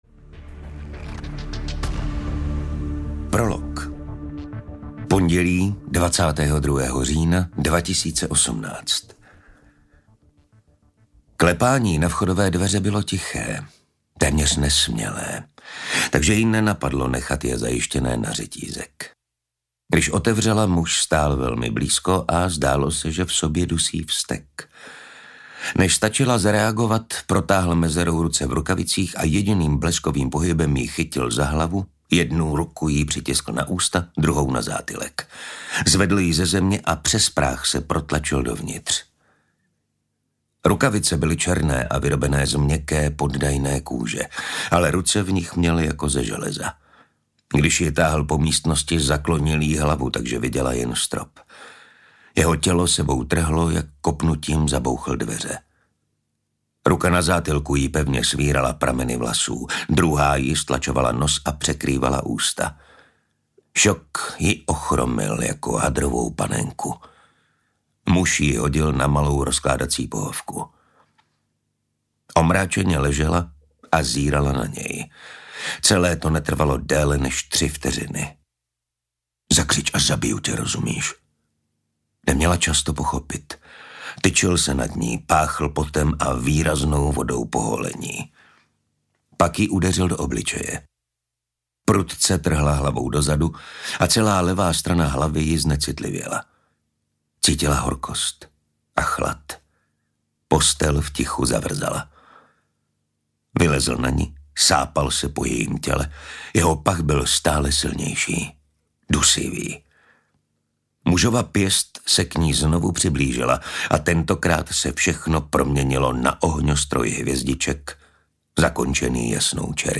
Osudné svědectví audiokniha
Ukázka z knihy
• InterpretMartin Stránský